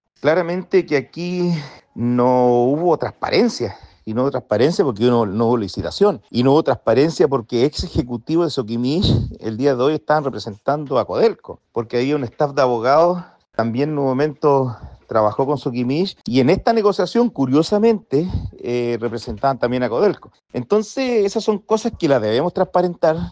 El diputado independiente pro PPD, Cristian Tapia, complementó que aún no se logra comprobar por qué la asociación no pasó antes por un proceso de licitación, favoreciendo a la minera no metálica.